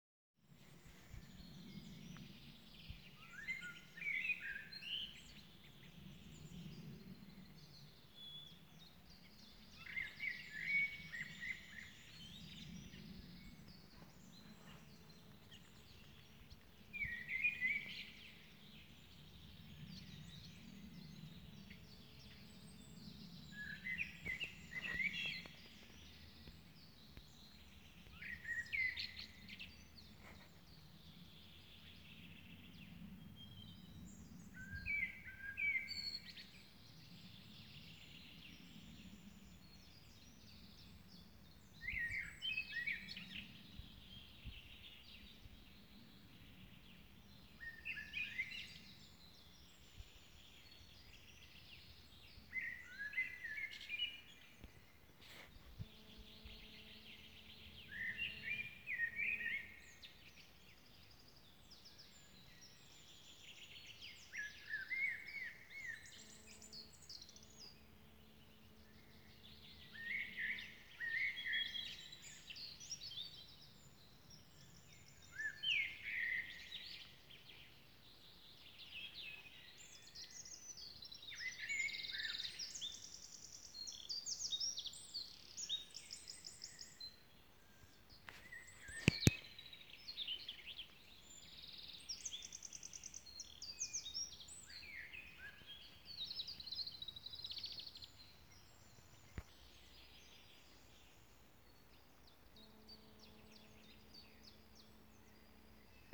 Deszcz oznacza pustkę w lesie.
Niesamowity śpiew ptaków.
Ptaki_Pojniki_WPN.m4a